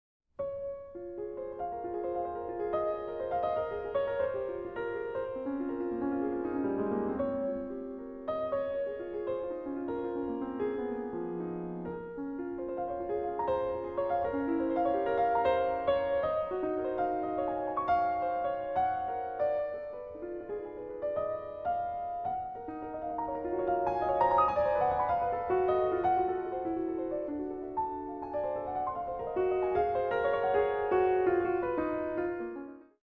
Works for piano